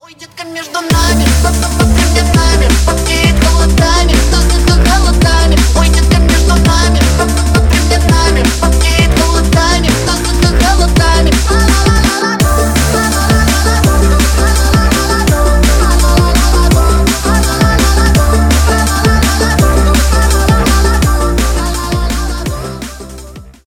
Ремикс
ритмичные # весёлые